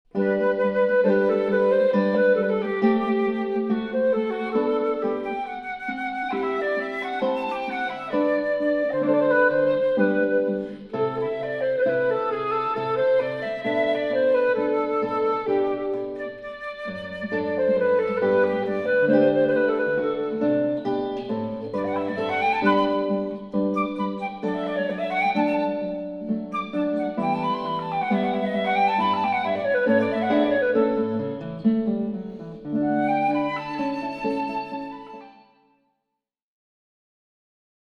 flute/guitar
a stereo remix with reverb as heard over only the left and right
I imagine sitting in my 5-ch listening room listening to stereo-speaker replay of a stereo recording of the duo playing in a concert hall.